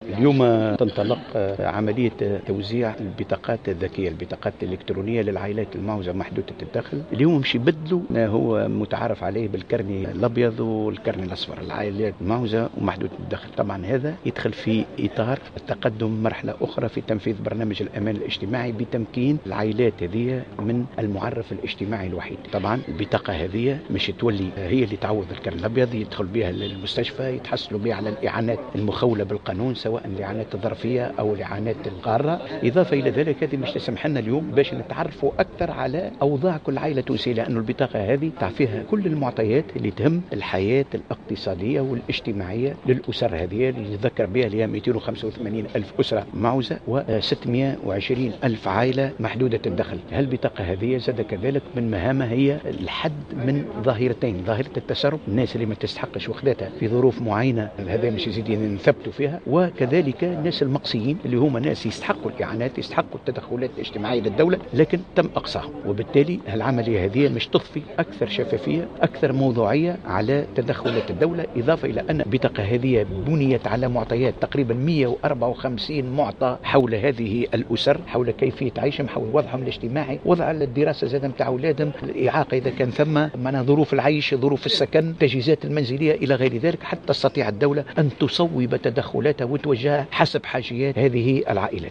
قال وزير الشؤون الإجتماعية محمد الطرابلسي في تصريح لمراسلة الجوهرة "اف ام" إنه سينطلق بداية من اليوم عمليات توزيع بطاقات الأمان الإلكترونية على العائلات المعوزة و محدودة الدخل موضحا أنها ستعوض البطاقتين البيضاء والصفراء.